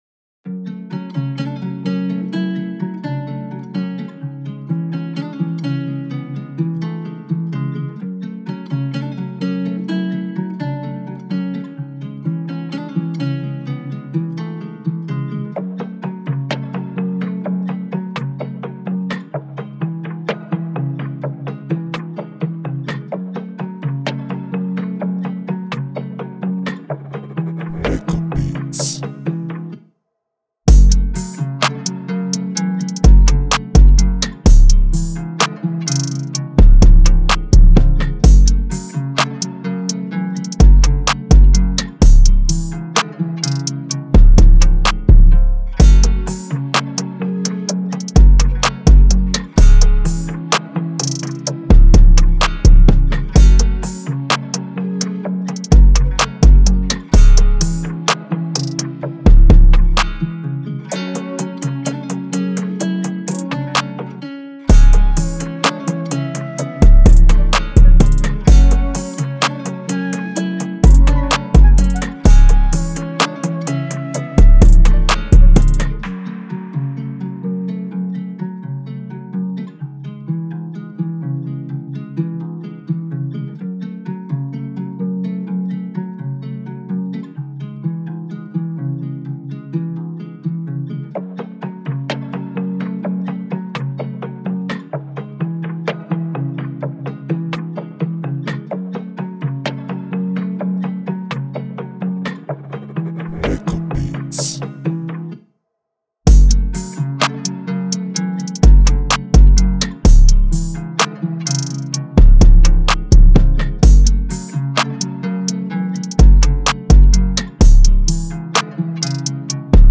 ژانر: بیت رپ
توضیحات: بیت رپ تند و گنگ
سبک:ترپ (گیتار )